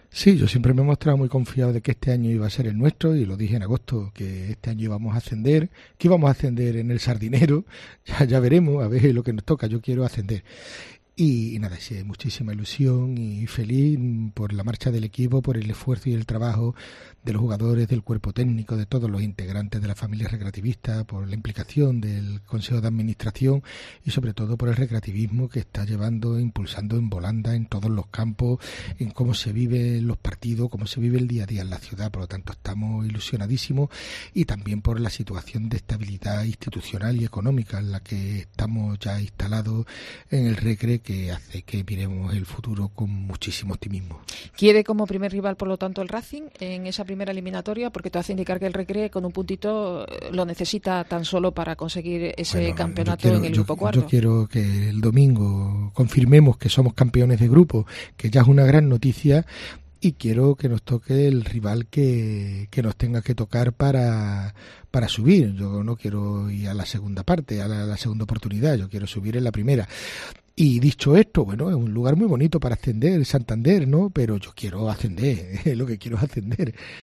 Gabriel Cruz , alcalde de Huelva en los estudios de COPE HUELVA